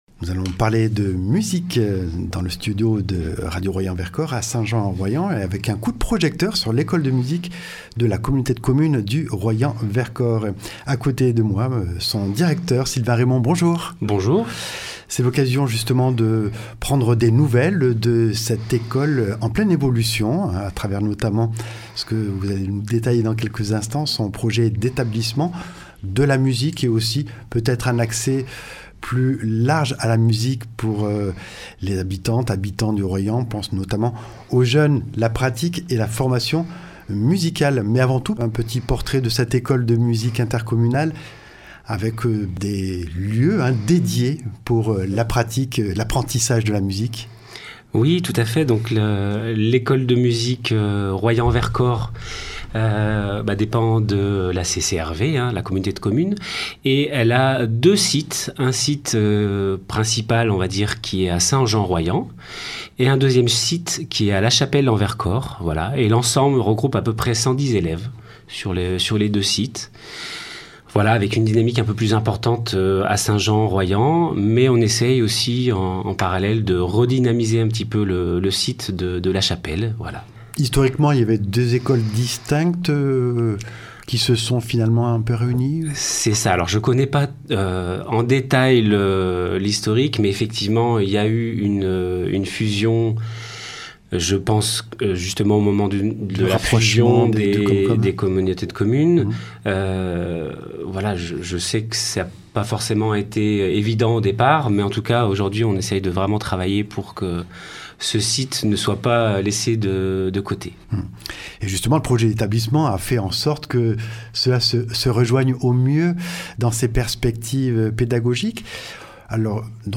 Entretien
Présentation de ses 2 antennes entre piémont et plateau, son projet pédagogique et le développement des pratiques musicales collectives et notamment en live dans l’espace public. Avec 2 captations sonores de l’ensemble Musac.